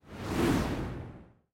balloon_deflate.mp3